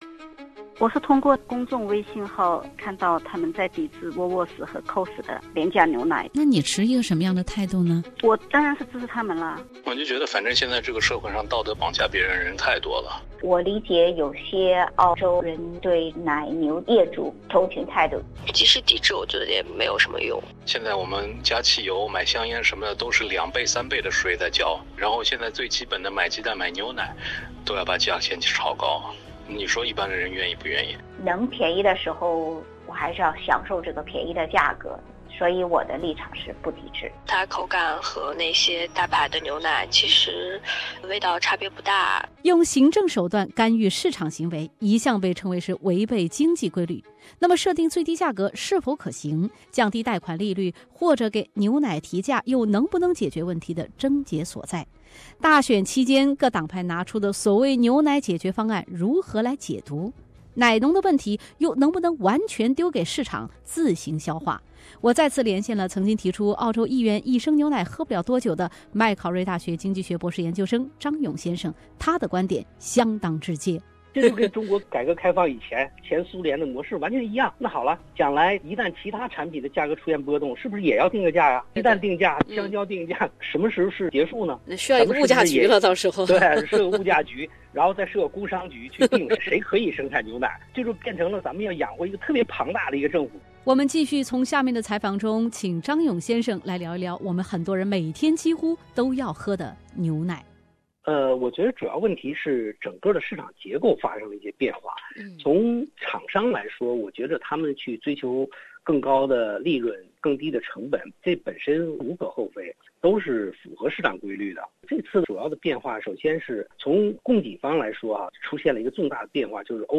深度报道：牛奶限价就是回归“计划经济”?